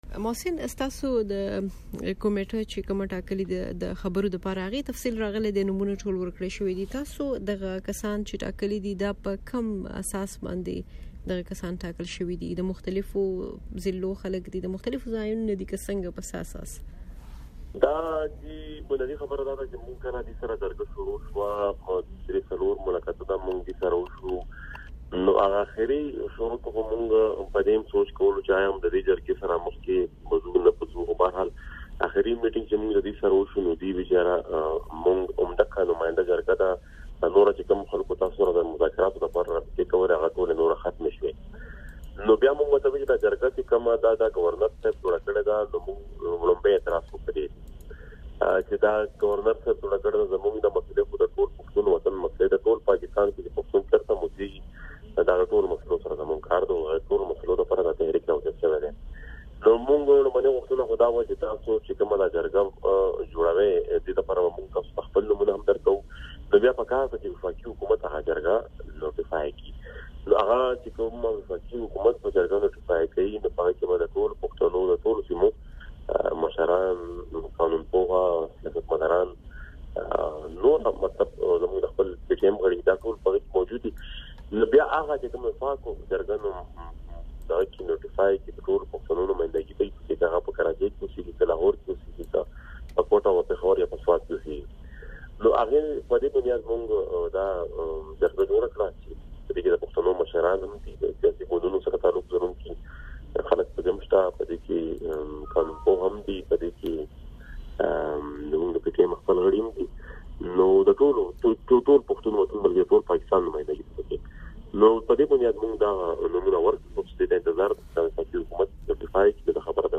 د محسن داوړ سره مرکه...